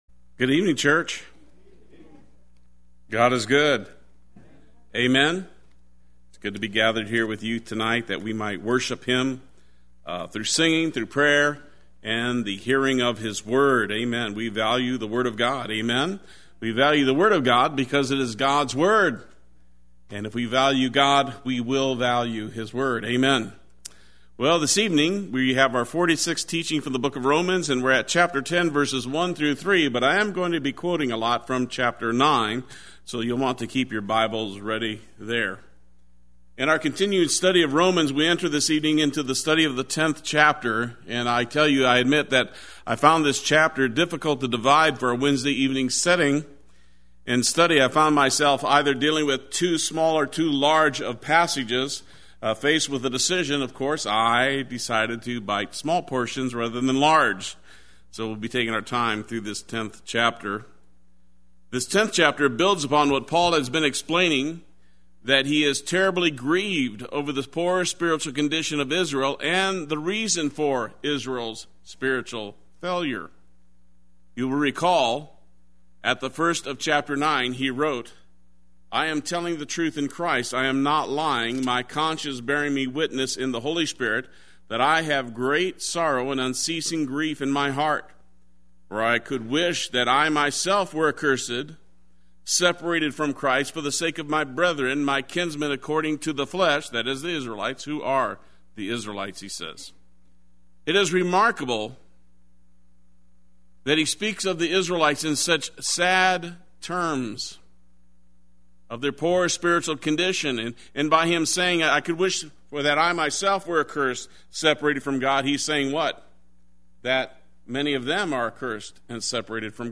Play Sermon Get HCF Teaching Automatically.
About God’s Righteousness Wednesday Worship